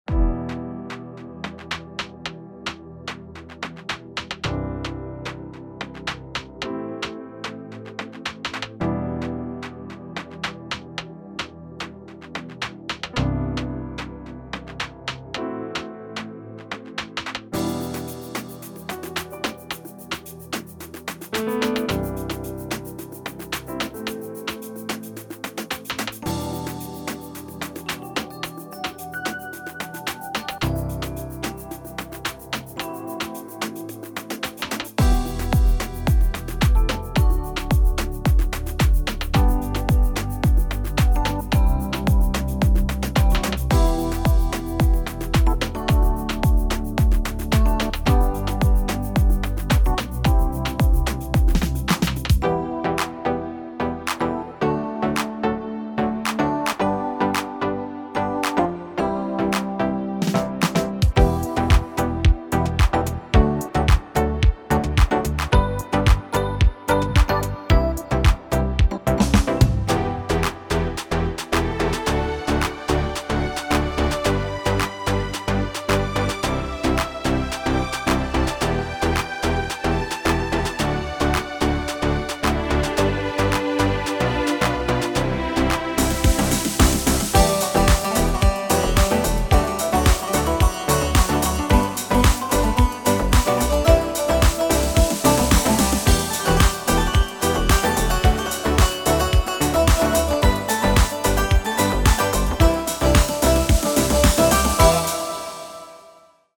06 Slow
אסטה-אלקטרוני_04.mp3